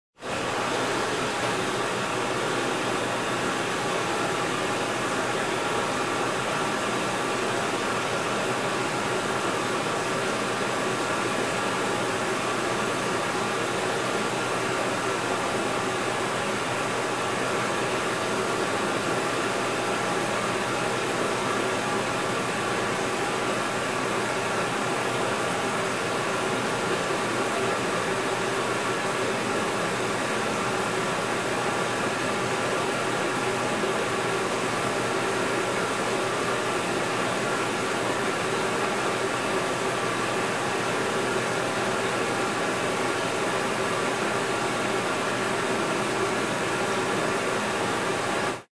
洗衣房 " 洗衣机 5
描述：洗衣机清洗一整件衣服。 用Zoom H4n机载立体声话筒录制。
Tag: 洗衣机 翻滚 机械 缩放 H4n 洗衣 旋转